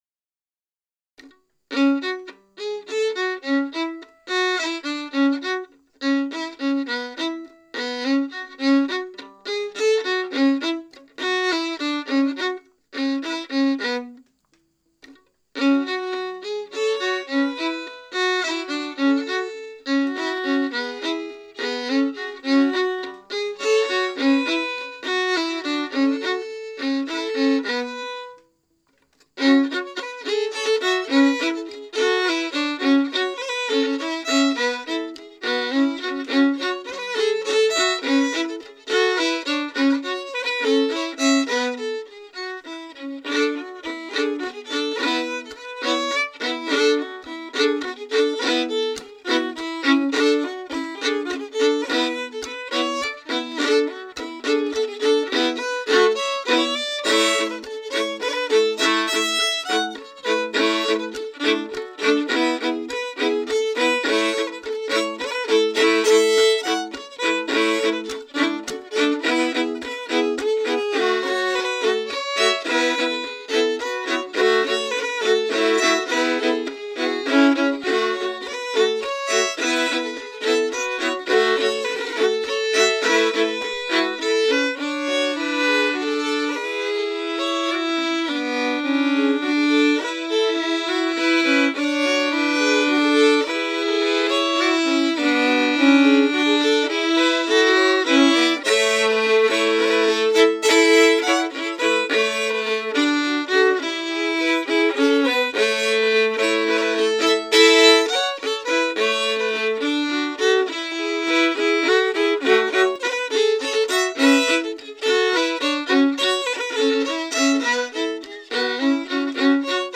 Key: F#m
Form: 3/2 hornpipe
As arranged for the Vermont Fiddle Orchestra
The melody is rather buried under the accompaniment, but it’s there.
2. Last (3rd) time through, the melody of the ”B’ part is missing, and in its place there’s a floaty interlude, reminiscent of the second part of the intro.”